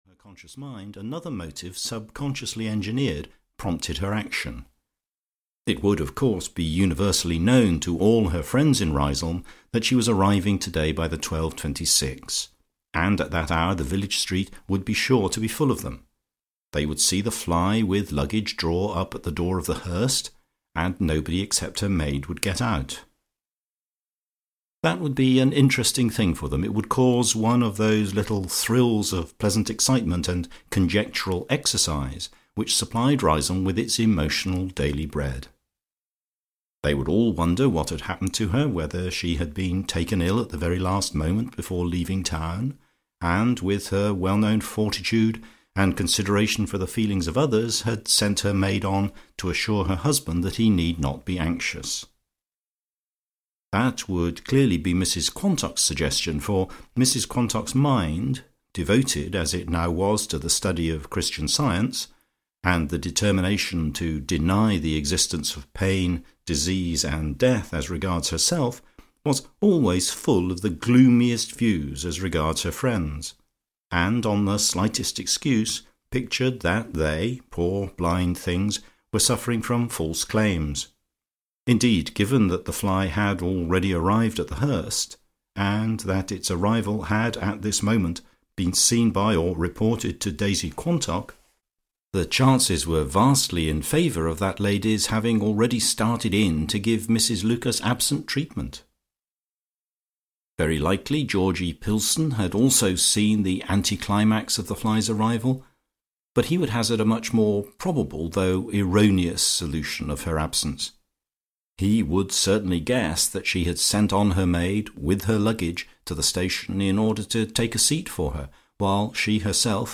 Queen Lucia (EN) audiokniha
Ukázka z knihy